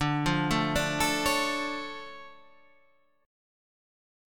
DmM7 chord